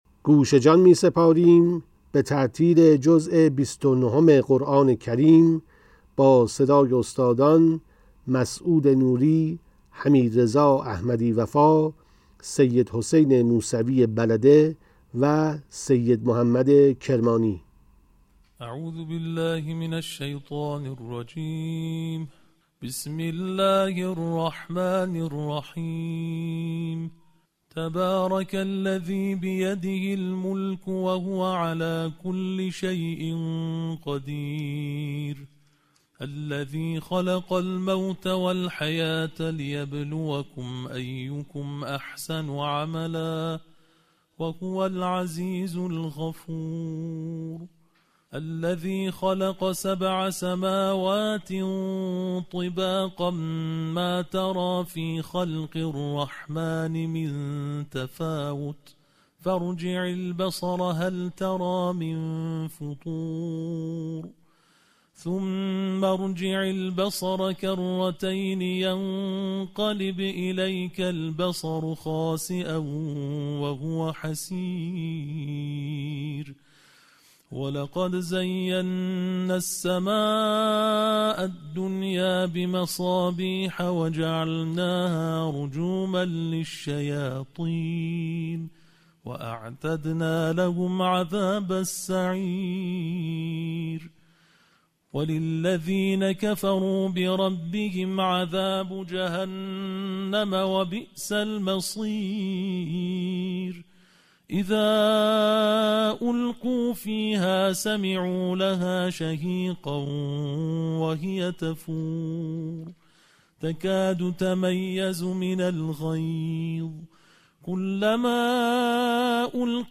قرائت ترتیل جزء بیست و نهم قرآن با صدای قاریان بین‌المللی + صوت
نسخه باکیفیت تلاوت جزء بیست و نهم قرآن با صدای قاریان بین‌المللی